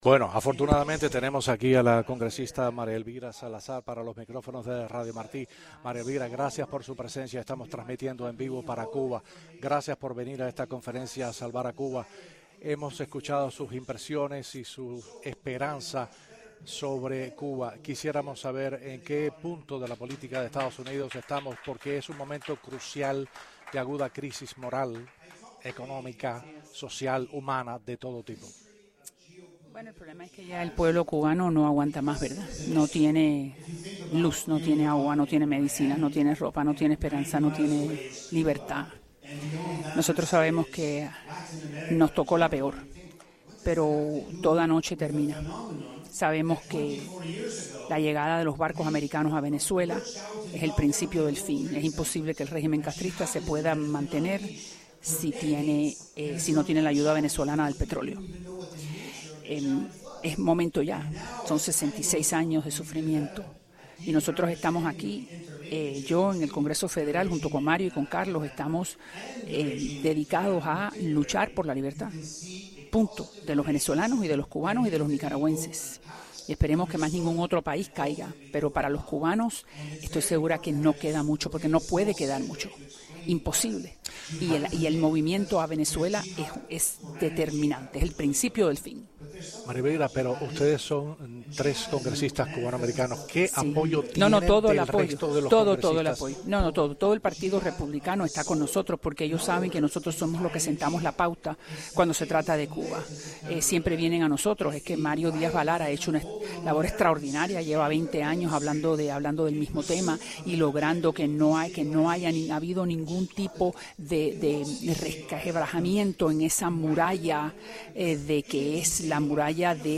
La congresista María Elvira Salazar dijo a Radio Martí que es imposible que el régimen castrista se pueda mantener, si no tiene la ayuda venezolana del petróleo.